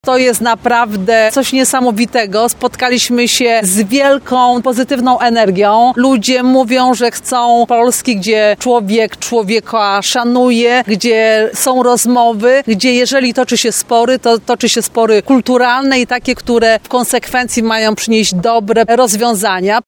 Spotkanie miało miejsce dziś na Placu Antoniego Surowieckiego w Tarnobrzegu.
Mówi eurodeputowana Elżbieta Łukacijewska.